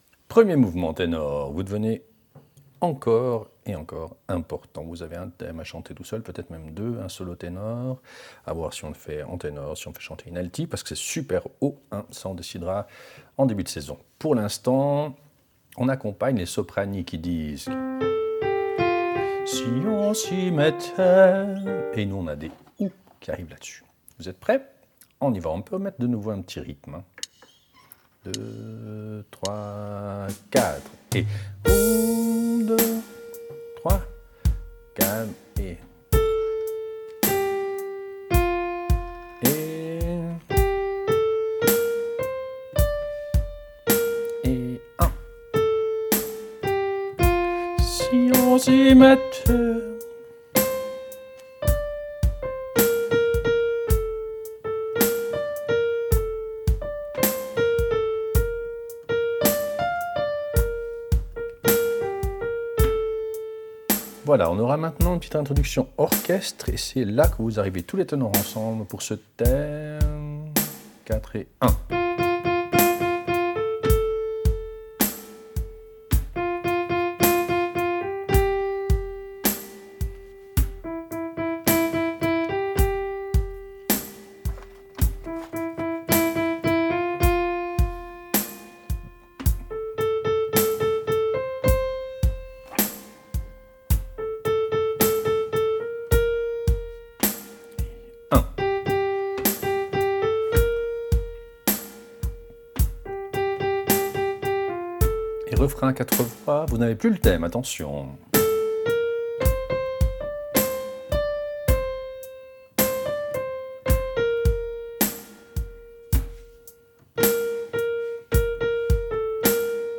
Répétition SATB4 par voix
Ténor